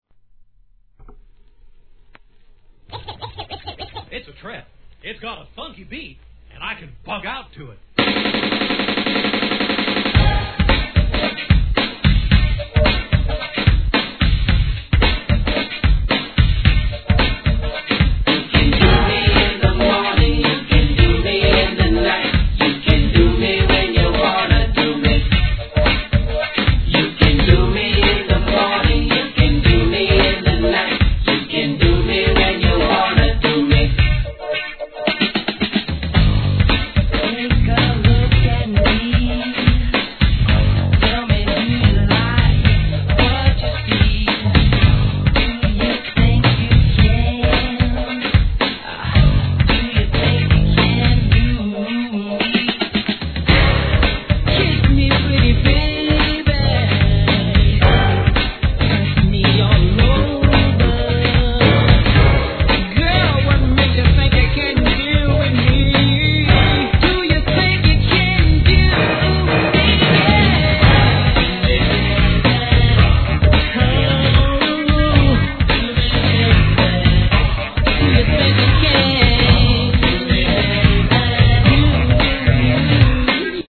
HIP HOP/R&B
FUNKYなNEW JACK SWING決定盤!!